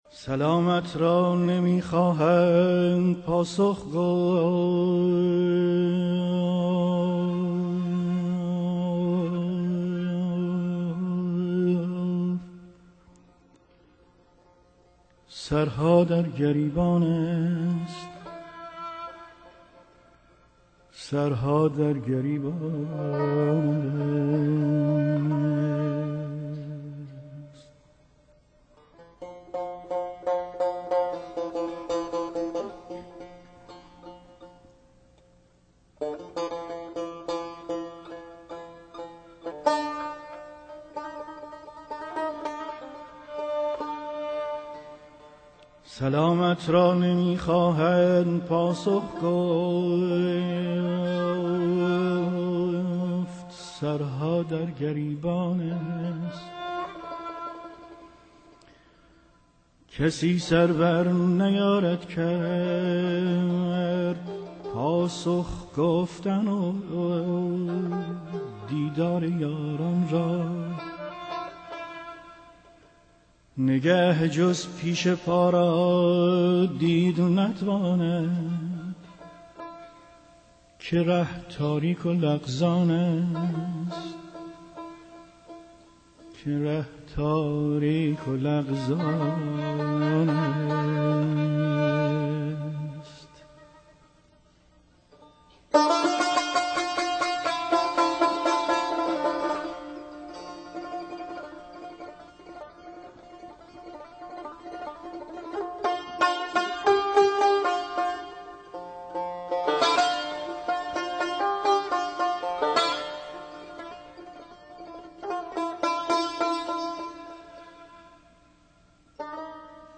آواز